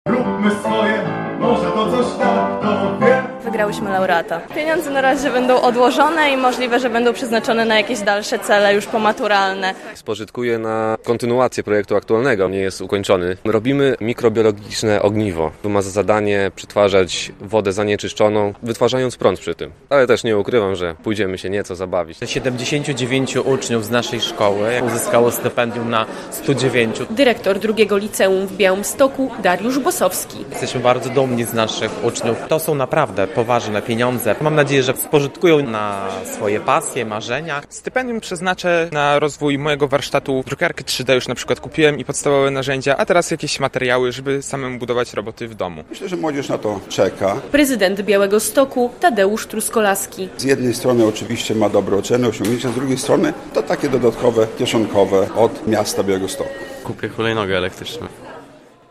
relacja
Na uroczystości był także prezydent Białegostoku Tadeusz Truskolaski.